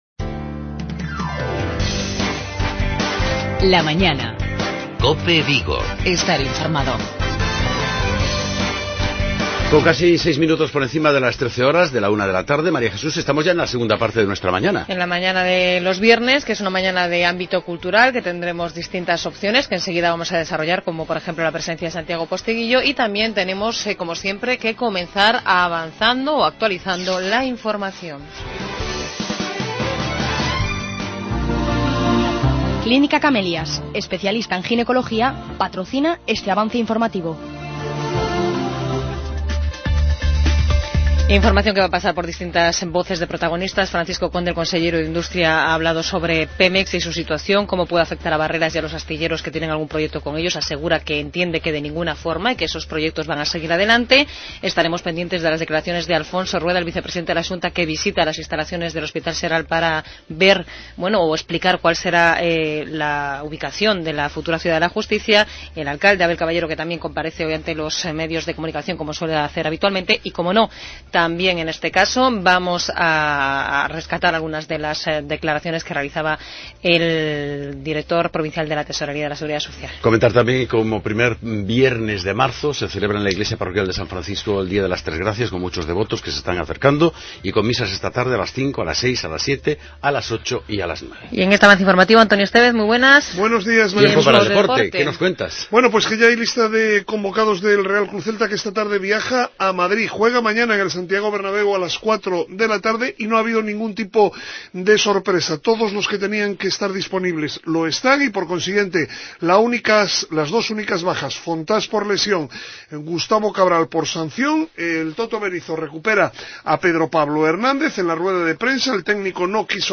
Acabamos la semana centrados en aspectos culturales. En primer lugar entrevistamos al reconocido escritor Santiago Posteguillo, que presenta nueva obra.